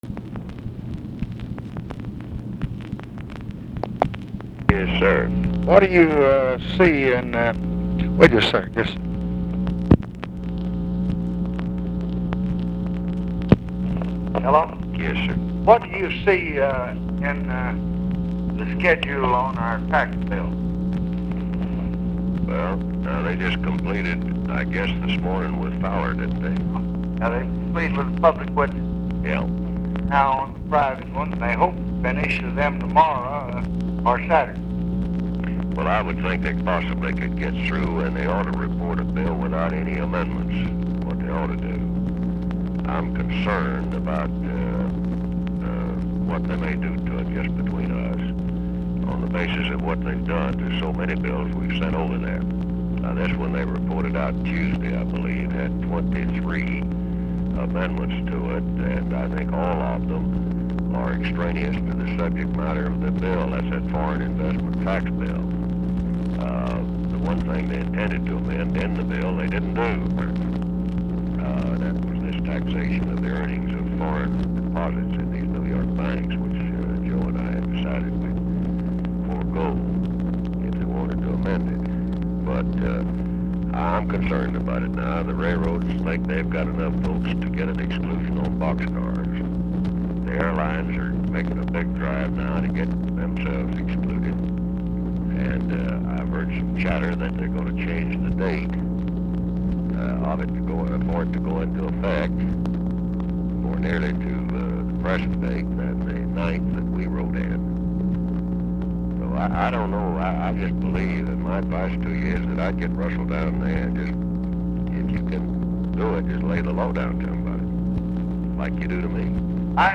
Conversation with WILBUR MILLS, October 6, 1966
Secret White House Tapes